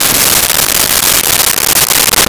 Splash Hard 01
Splash Hard 01.wav